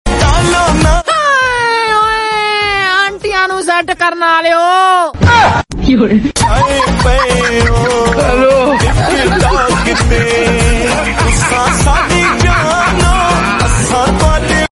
hahahaha😁😁😁 sound effects free download